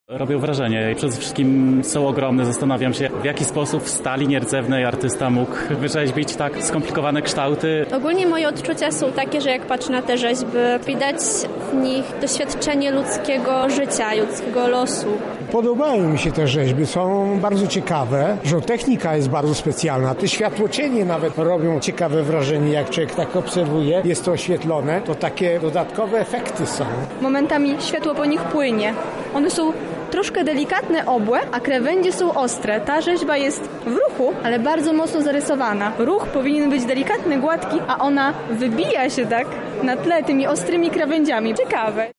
Odwiedziliśmy wernisaż Adama Myjaka w Centrum Spotkania Kultur.
O wrażenia zapytaliśmy także gości wystawy.